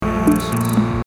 Привет, подскажите пожалуйста название трескающего звука